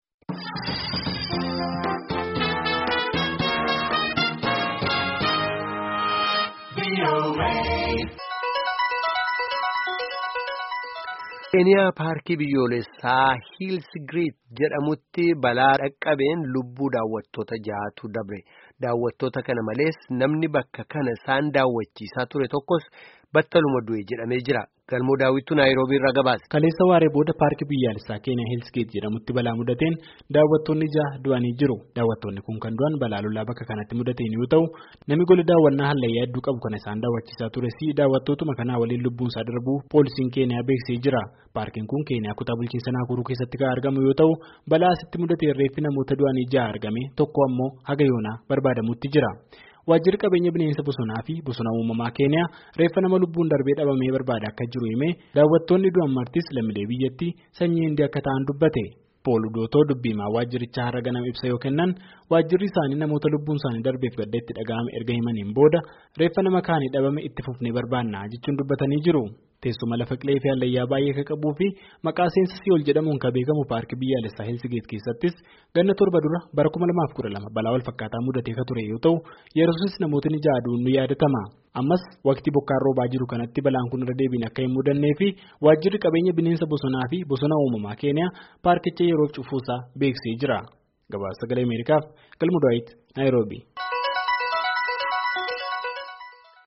Gabaasa guutu caqasaa